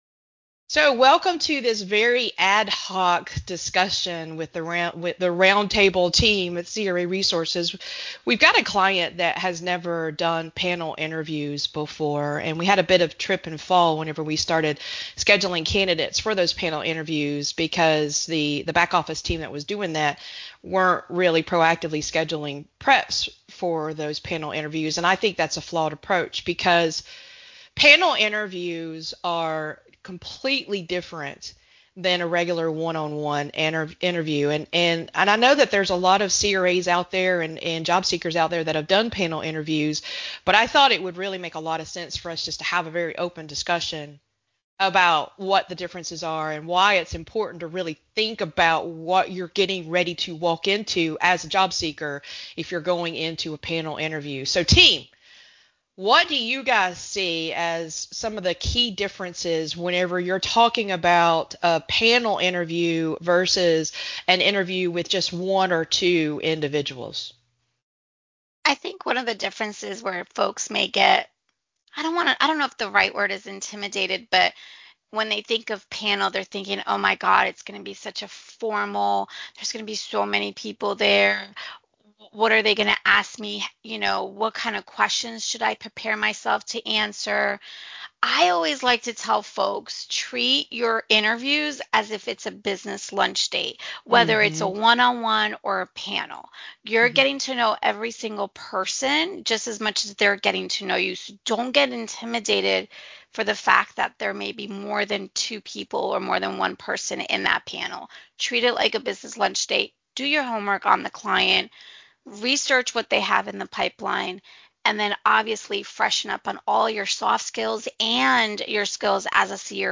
Roundtable: How to Prepare for a Panel Interview - craresources
Roundtable-How-to-Prepare-for-a-Panel-Interview.mp3